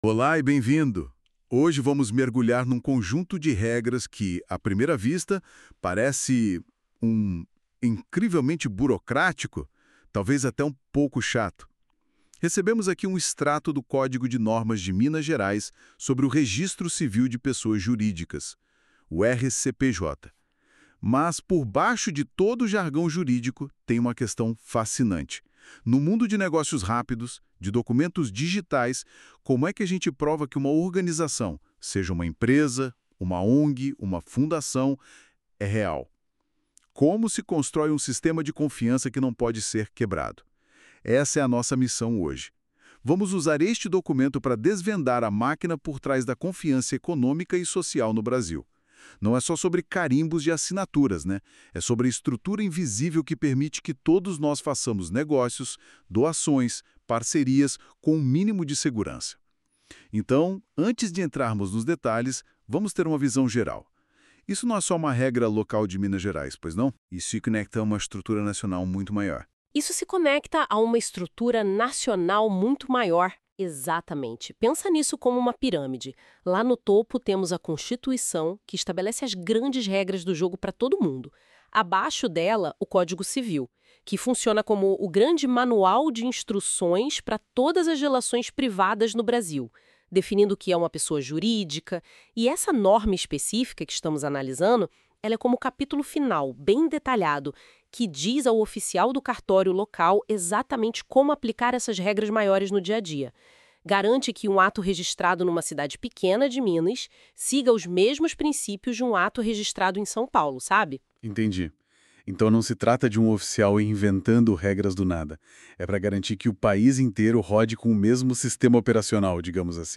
Aula_01_-_O_registro_civil_como_maquina_de_confianca.mp3